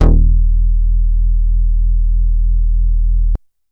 808s
G_07_Bass_03_SP.wav